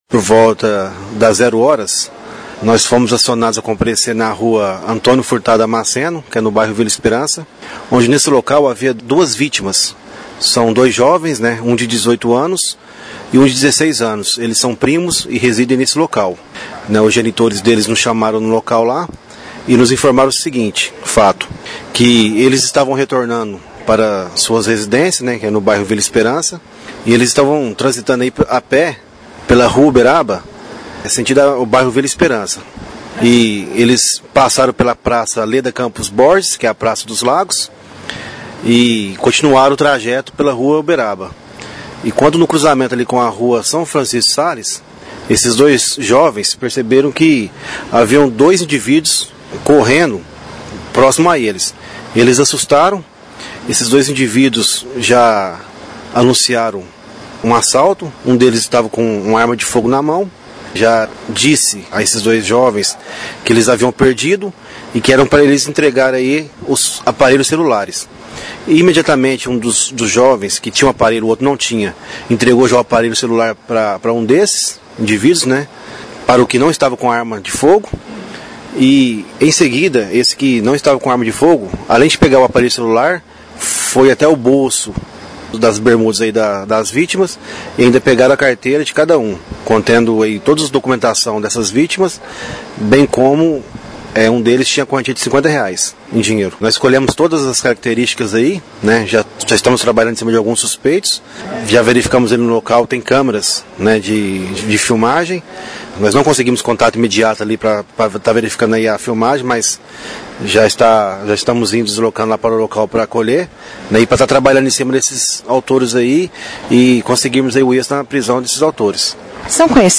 (Clique no player abaixo e ouça a entrevista). O roubo aconteceu no cruzamento das Ruas São Francisco de Sales e Uberaba, onde as vítimas foram abordadas por duas pessoas, uma delas com arma de fogo.